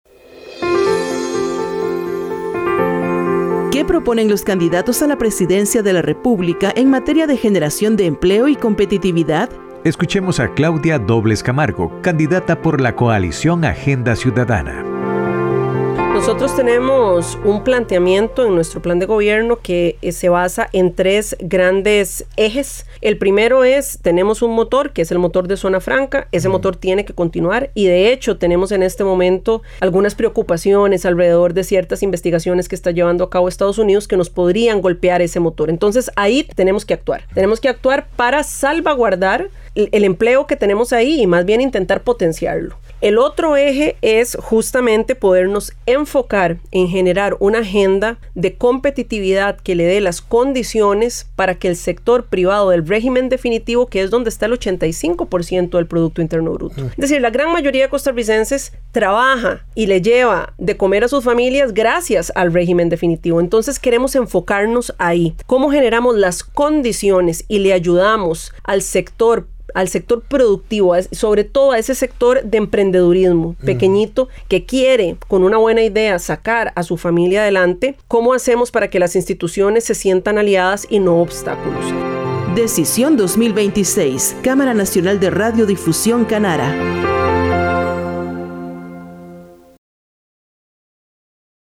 Escuchemos a Claudia Dobles Camargo, candidata de la Coalición Agenda Ciudadana.